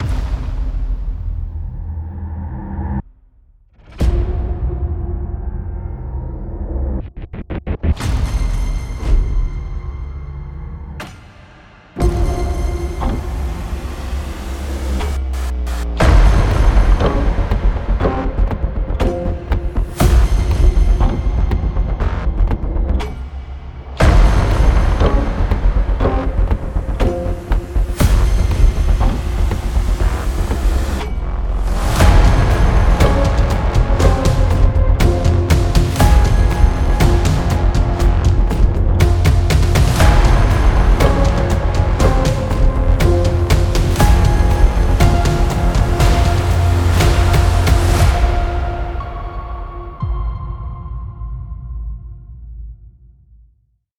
Genre: trailer, production.